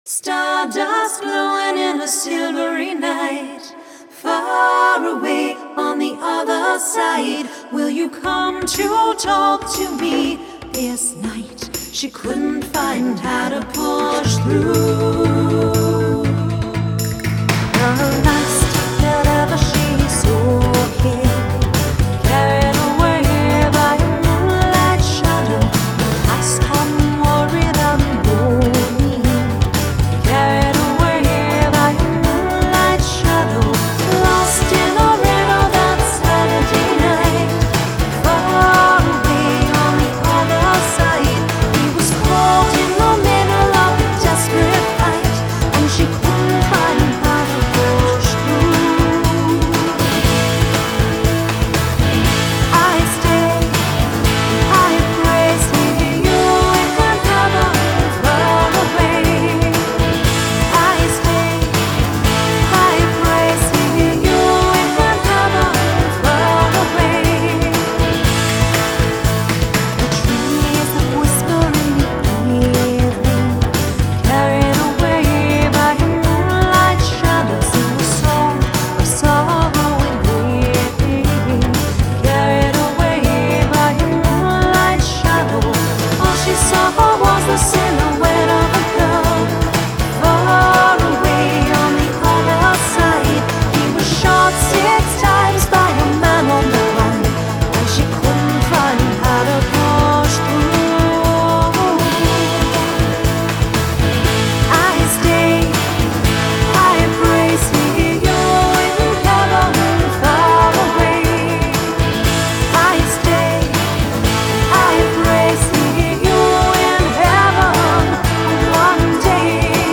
Genre: Folk Rock, Celtic, Medieval